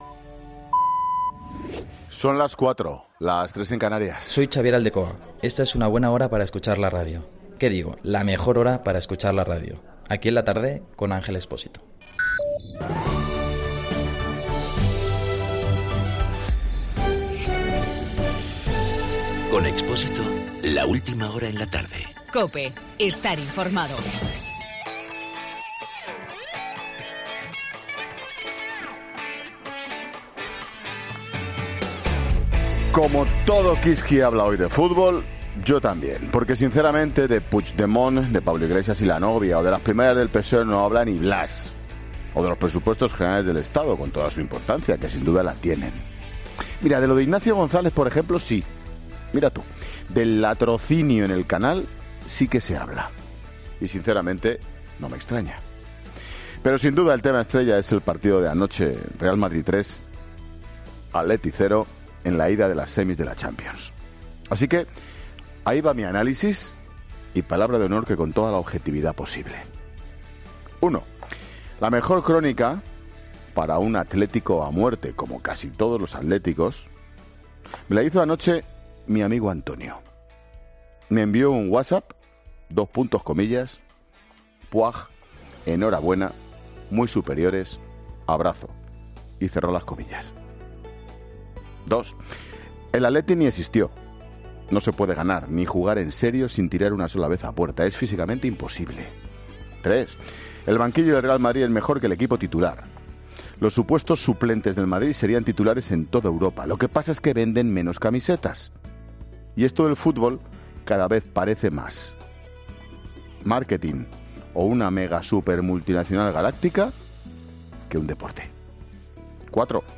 AUDIO: Monólogo 16h.